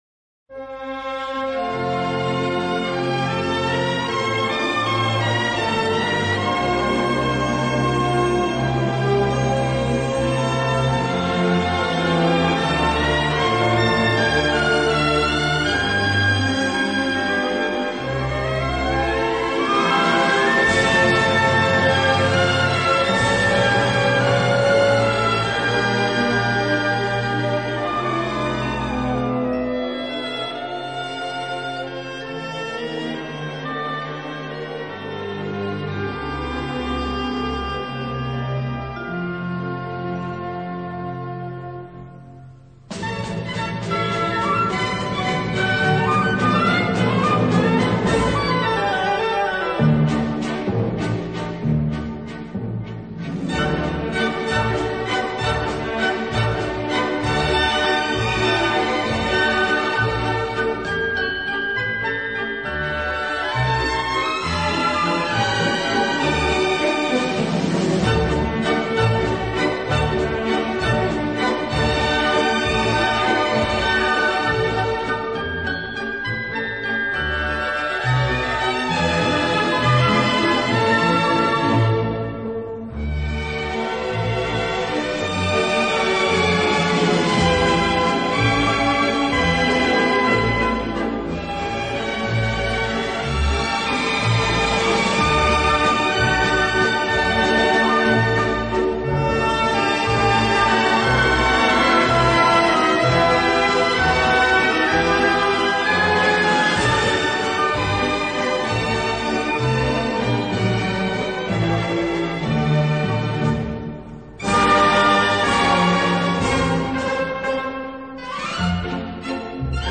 ЗДЕСЬ и ЗДЕСЬ (это на сколько мне известно - Венский оркестр)